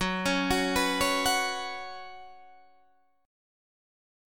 F#sus4 chord